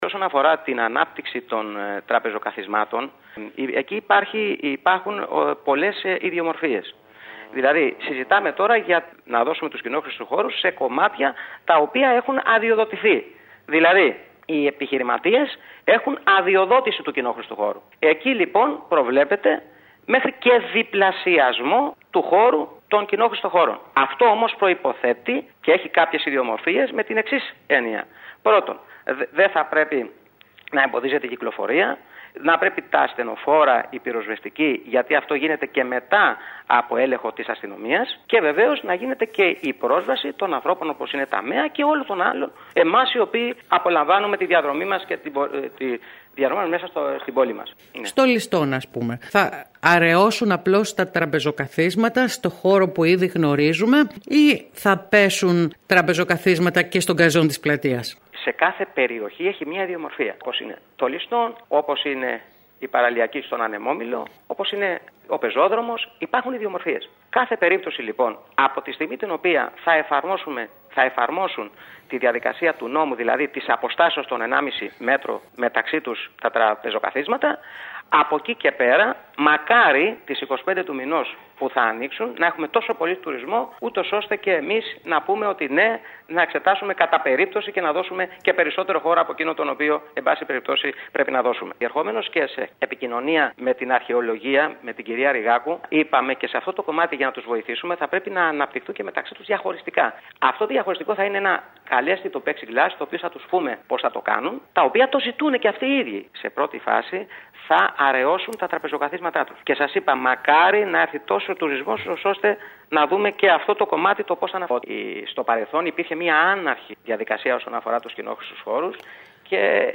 Κατά περίπτωση θα εξετάσει ο Δήμος Κεντρικής Κέρκυρας τη δυνατότητα διπλασιασμού της διάθεσης κοινόχρηστου χώρου στα καταστήματα εστίασης δηλώνει ο αρμόδιος αντιδήμαρχος Σπύρος Μωραΐτης. Ο κος Μωραΐτης, μιλώντας σήμερα στην ΕΡΑ ΚΕΡΚΥΡΑΣ, είπε ότι αρχικά ο Δήμος θα ζητήσει από τους επαγγελματίες να αραιώσουν τα τραπεζοκαθίσματα στους χώρους για τους οποίους έχουν ήδη αδειοδοτηθεί και στη συνέχεια αν υπάρξει μεγάλο τουριστικό ρεύμα θα εξεταστούν όλες οι δυνατότητες παροχής περισσότερων τετραγωνικών.